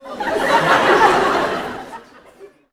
Increased volume of laugh tracks again
Audience Laughing-05.wav